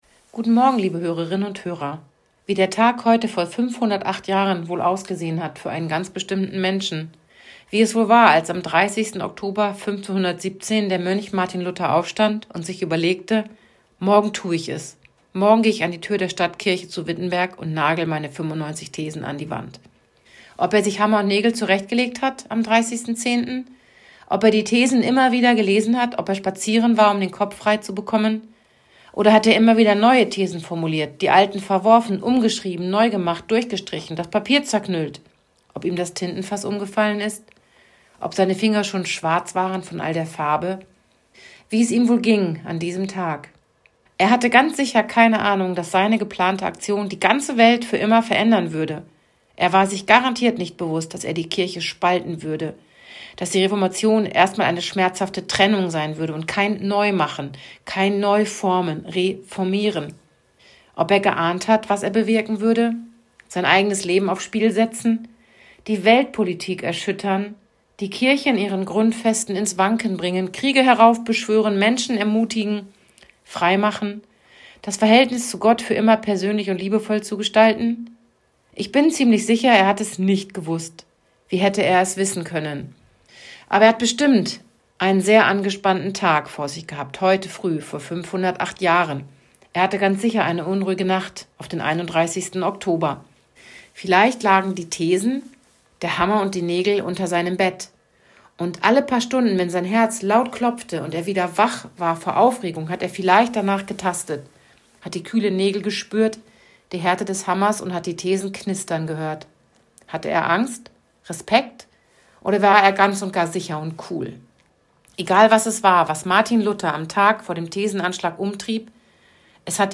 Hameln-Pyrmont: Radioandacht vom 30. Oktober 2025